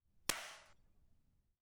Metal_95.wav